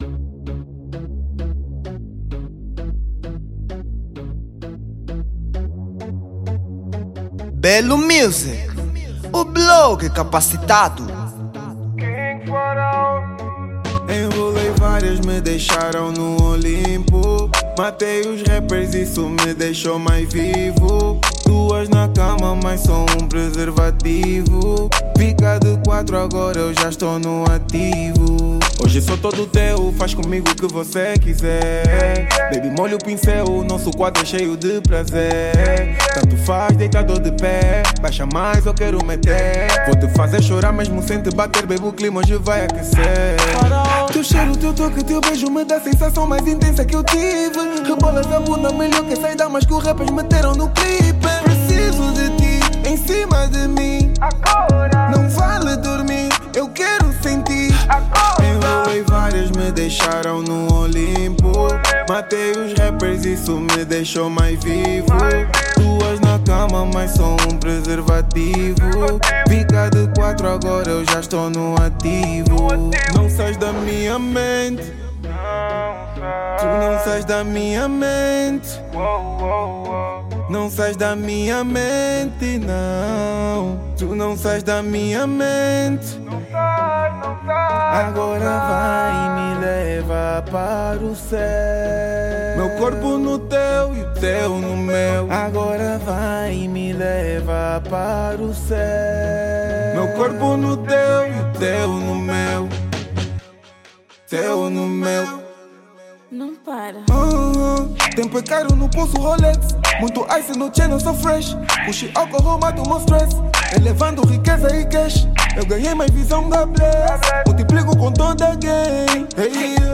Rap Forma...